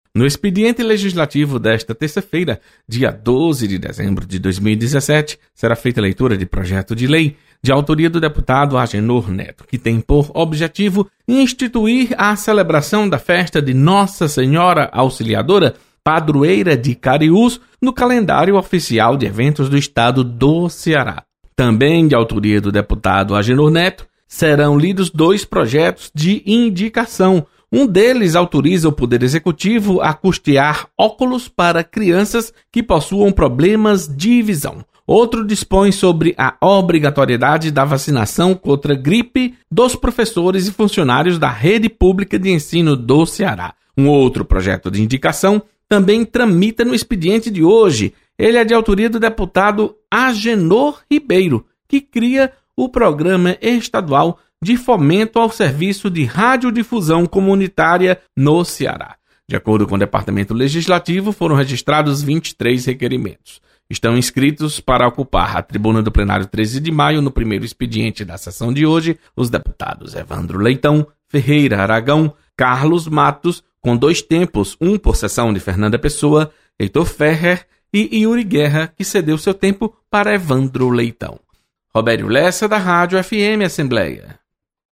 Você está aqui: Início Comunicação Rádio FM Assembleia Notícias Expediente